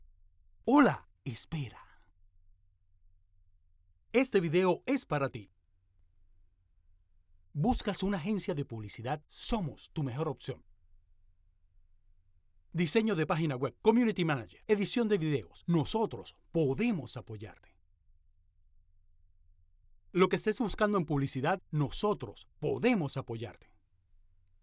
Male
Adult (30-50)
Mi acento nativo es Venezolano, con manejo del acento neutro, mi voz puede ser versátil y agradable, con una buena dicción y conocimiento profesional y técnico de la locucion, junto a la creatividad, flexibilidad y empatía con tus ideas.
Casting Voz En Off Para Video
0401Voz_en_off_para_video.mp3